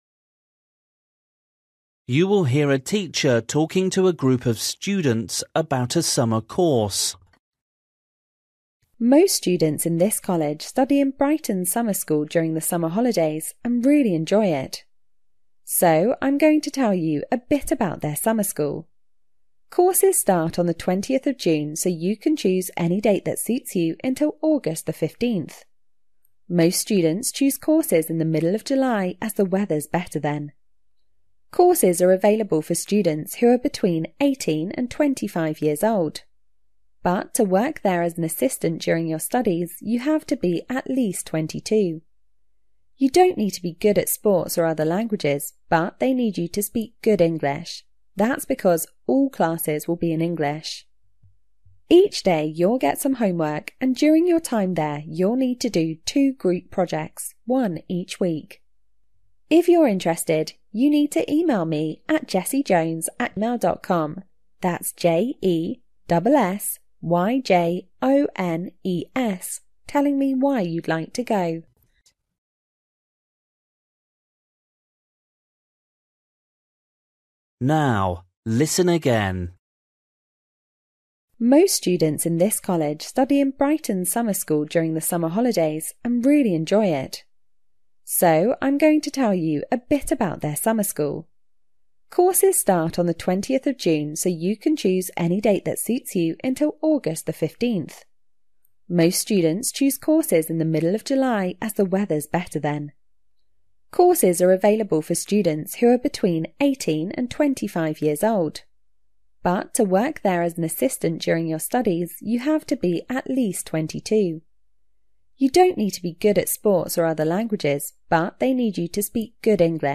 You will hear a teacher talking to a group of students about a summer course.